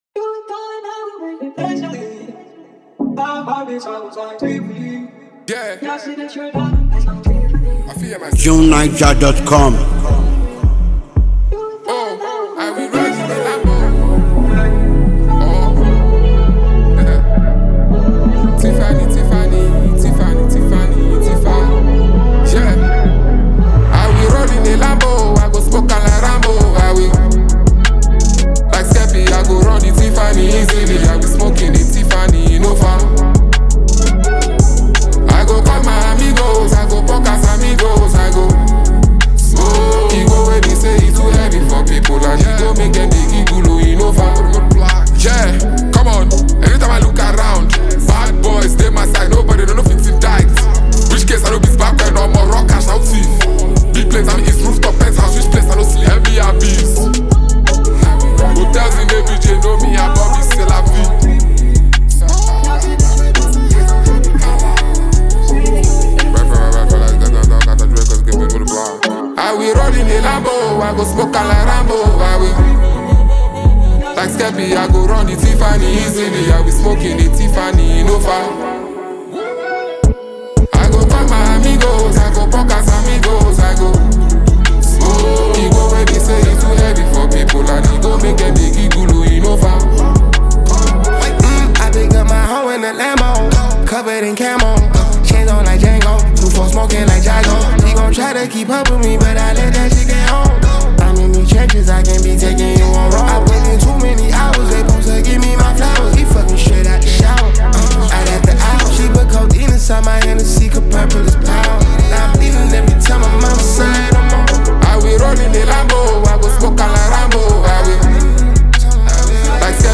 versatile Nigerian rapper, lyricist, and performer